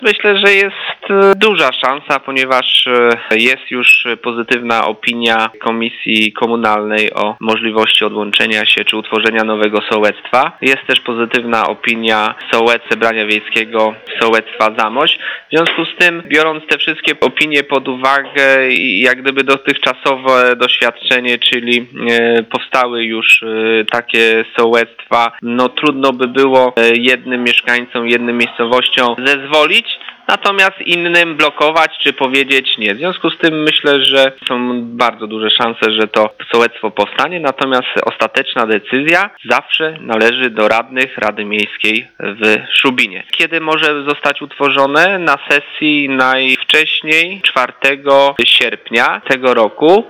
Jaka jest szansa na to, że nowe sołectwo powstanie oraz kiedy rada weźmie wniosek pod obrady mówi Mariusz Piotrkowski, zastępca burmistrza Szubina.